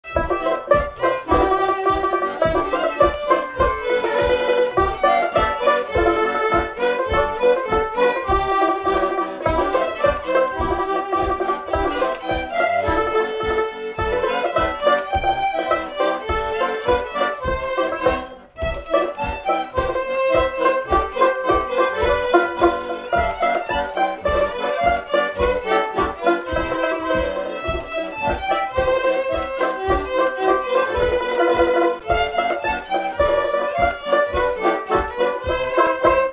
Mémoires et Patrimoines vivants - RaddO est une base de données d'archives iconographiques et sonores.
Instrumental
danse : polka
Pièce musicale inédite